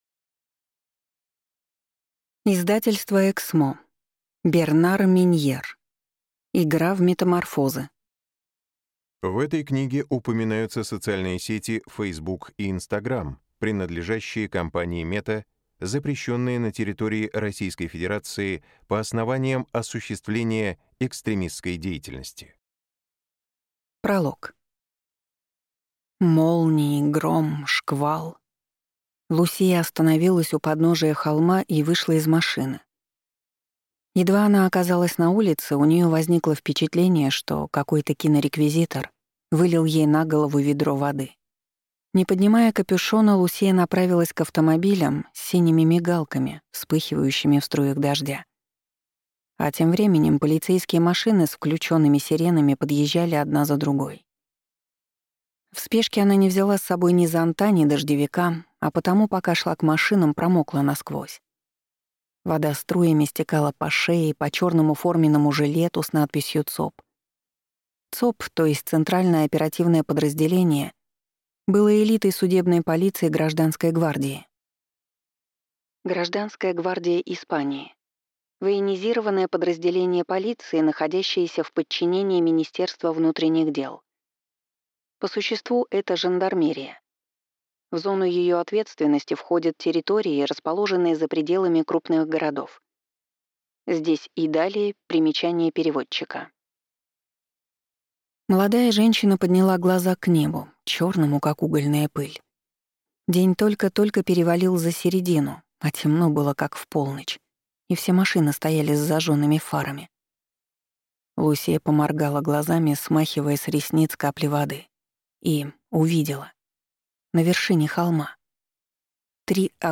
Аудиокнига Игра в метаморфозы | Библиотека аудиокниг